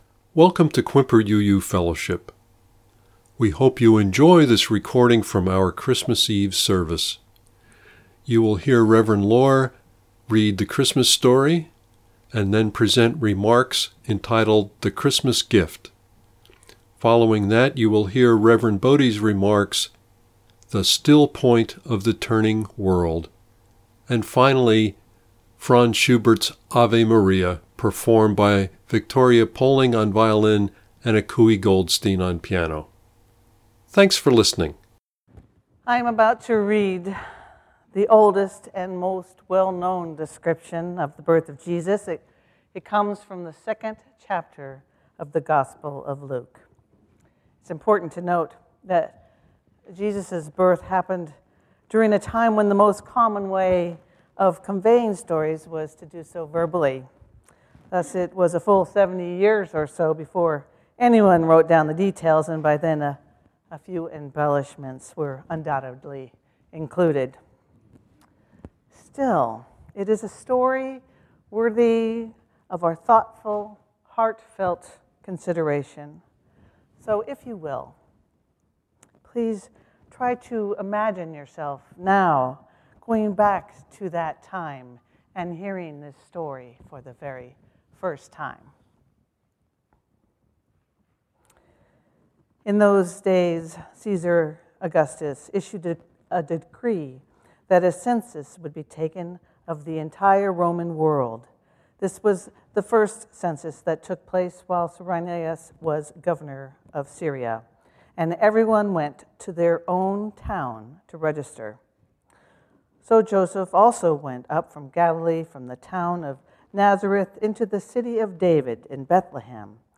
However, there were three services in the evening.
Click here to listen to the reading and meditations .